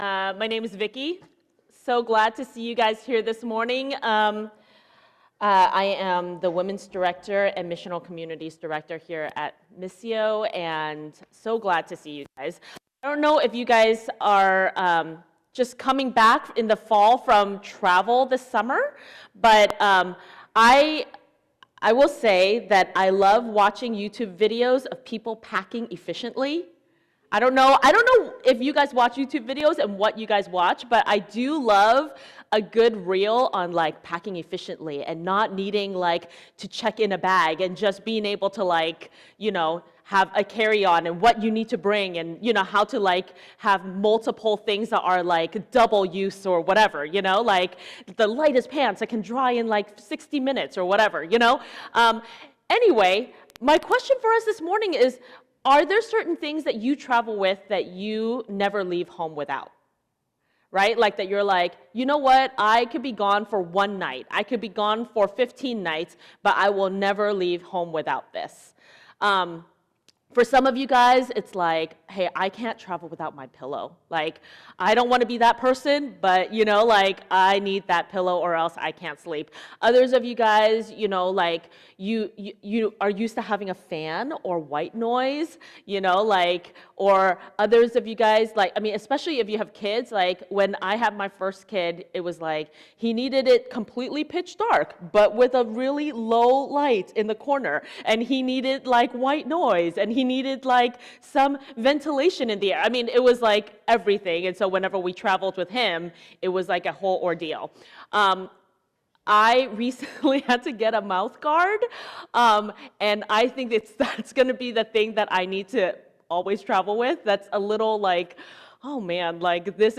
Sermons | Missio Community